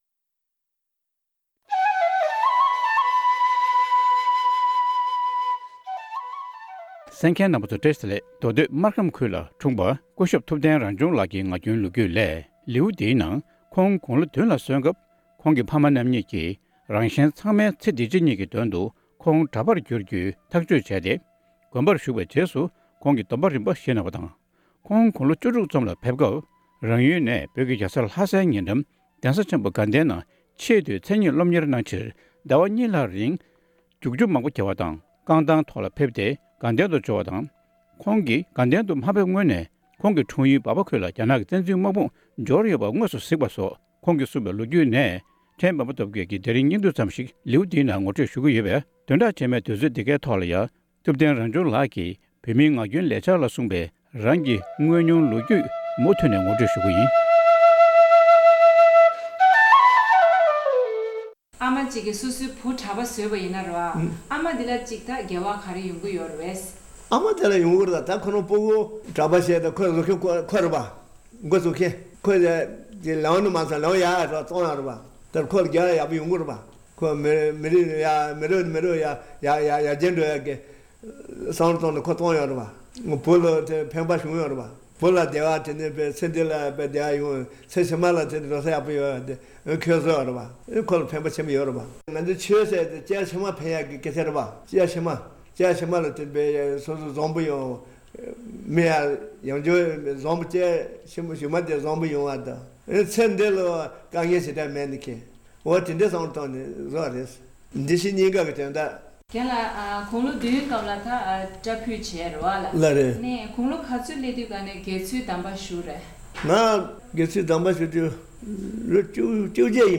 བོད་མིའི་ངག་རྒྱུན་ལས་འཆར།